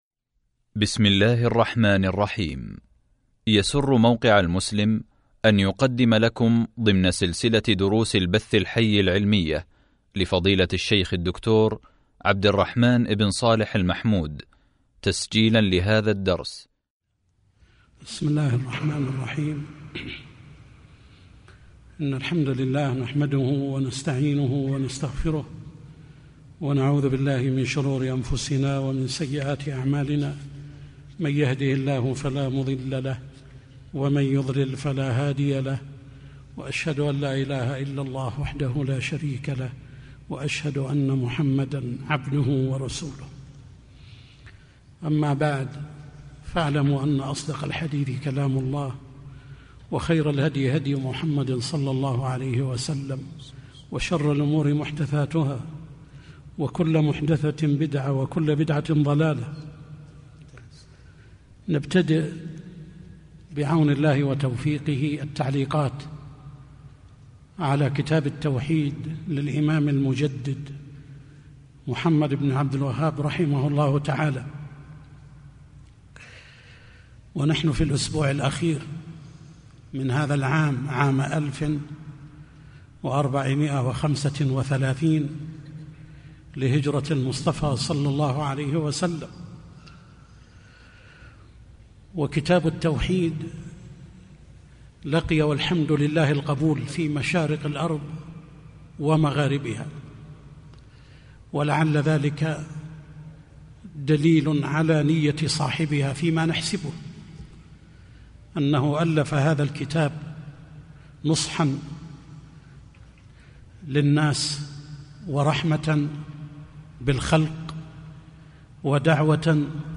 شرح كتاب التوحيد | الدرس 1 | موقع المسلم